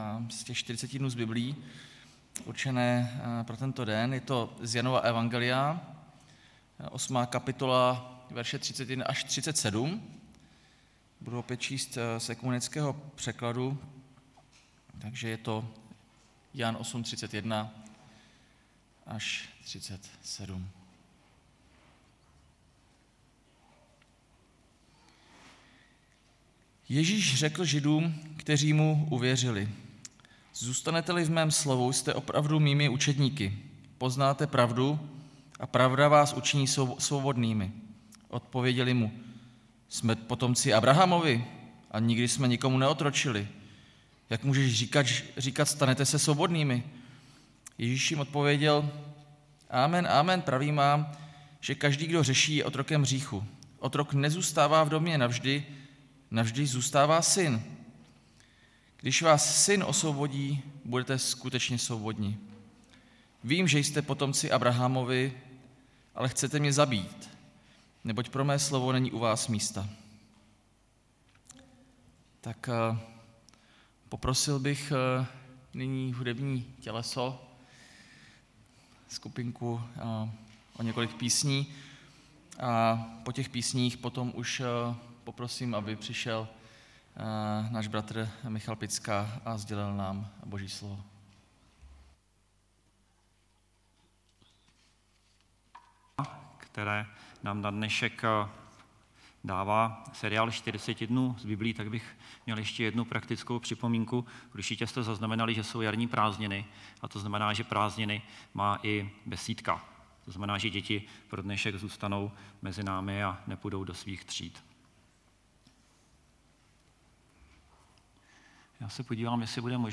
Nedělní bohoslužba